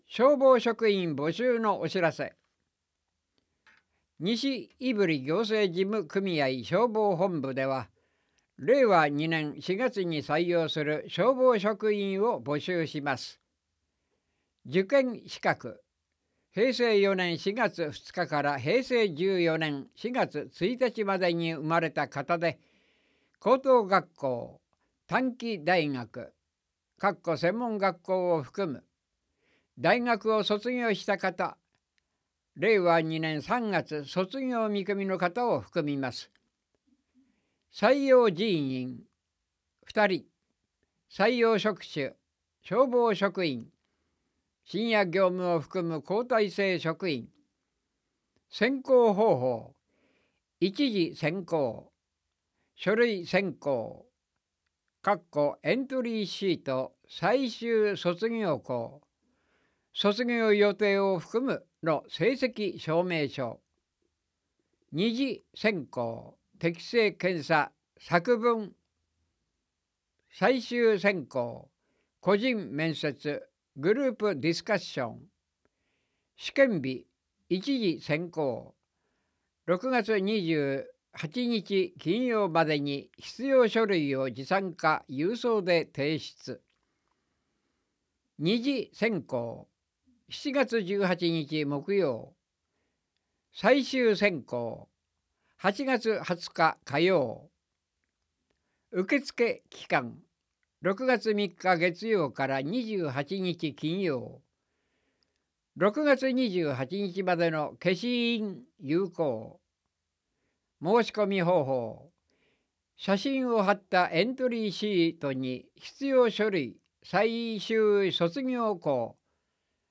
■朗読ボランティア「やまびこ」が音訳しています